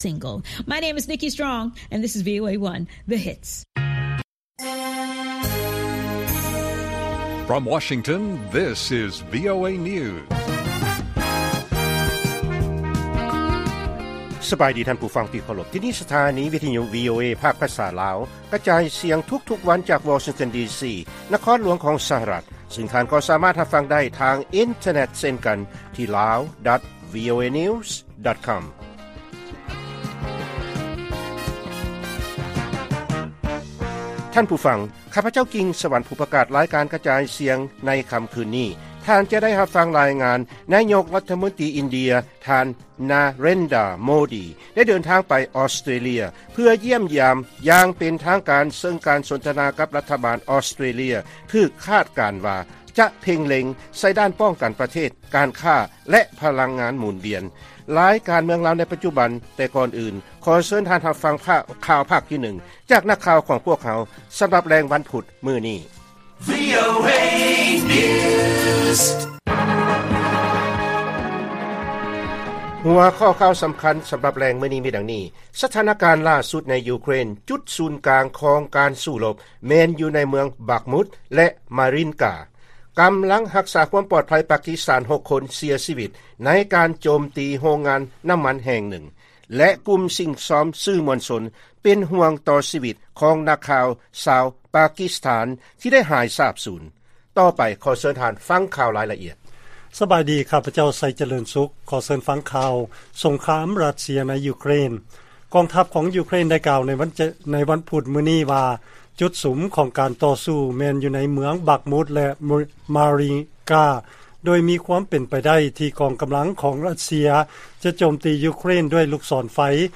ລາຍການກະຈາຍສຽງຂອງວີໂອເອ ລາວ: ສະຖານະການຫຼ້າສຸດໃນຢູເຄຣນ ຈຸດສູນກາງຂອງການສູ້ລົບ ແມ່ນຢູ່ໃນເມືອງບັກມຸດ ແລະ ມາຣິນກາ